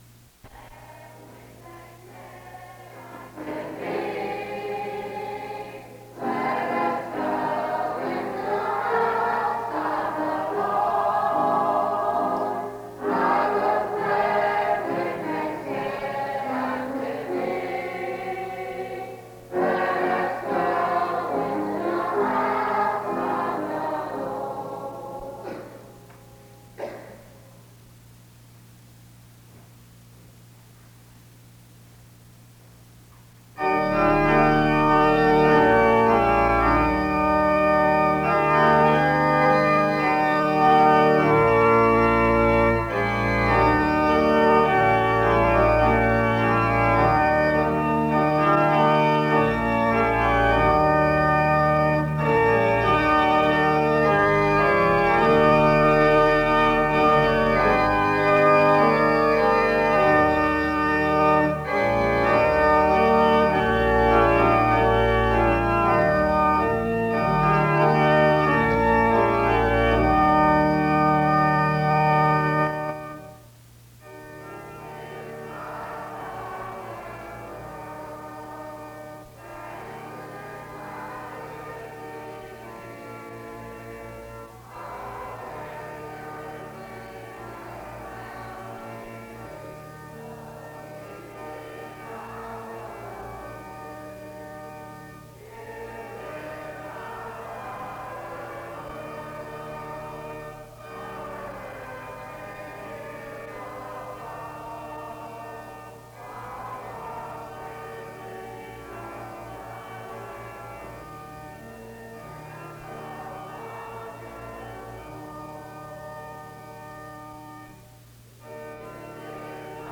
Download .mp3 Description The service opens with music from 0:00-3:35.
Music plays from 4:45-6:20.
More music plays from 10:44-12:47.Several speakers share what they are thankful for from 12:51-24:20.